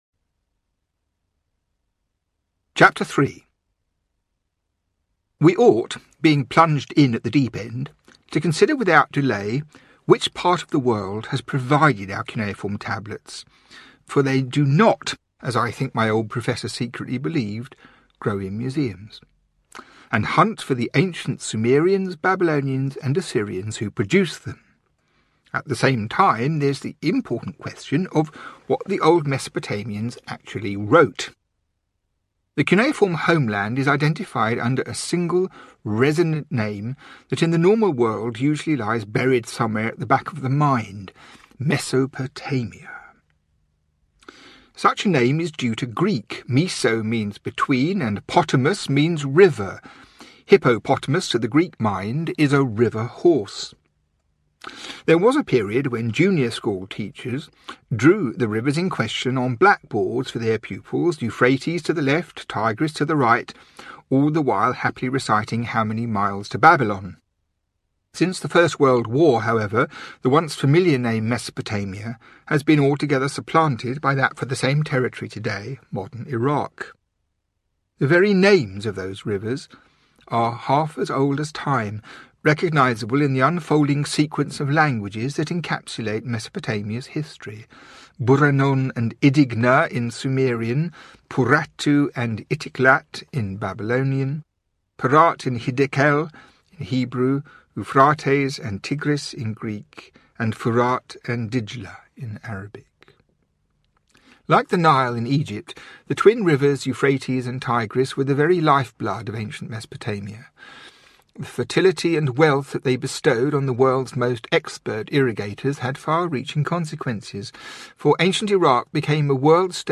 The Ark Before Noah Audiobook
9.0 Hrs. – Unabridged